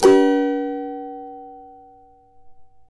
CAVA G#MJ  U.wav